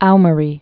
(oumə-rē, äô-môrē)